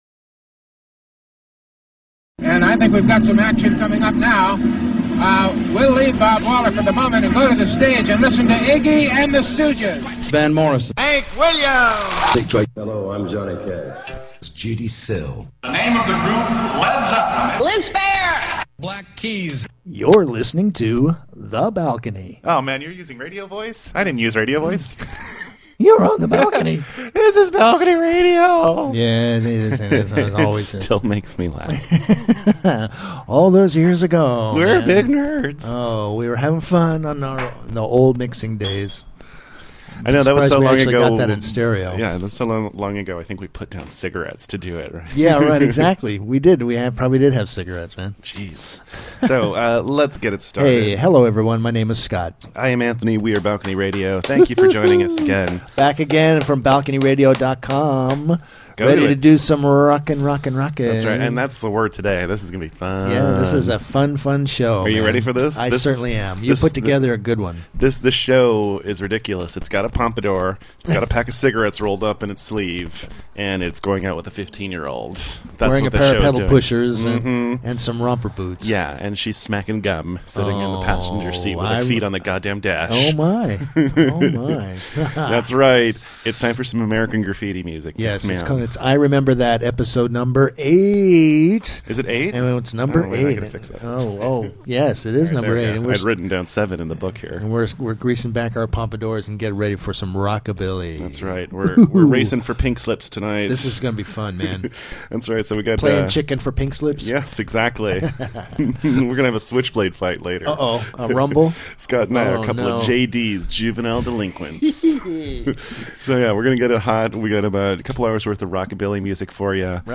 It’s episode 8 of our rock ‘n’ roll rerun show I Remember That and this week we step out and paint the town red with some serious Rockabilly. It’s time to swing on up to the bandstand for 90 mins of boogie time.
IRememberThat_Ep08_RockABilly.mp3